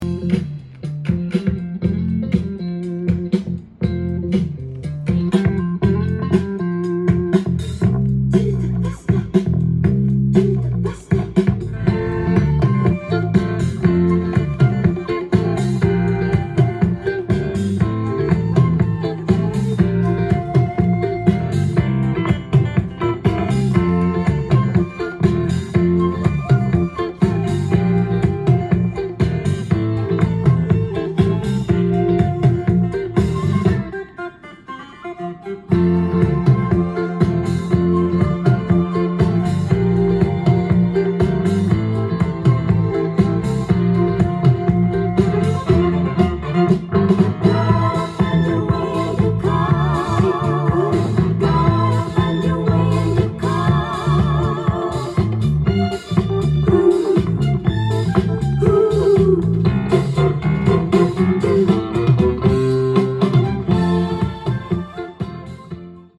ジャンル：Soul-7inch-全商品250円
店頭で録音した音源の為、多少の外部音や音質の悪さはございますが、サンプルとしてご視聴ください。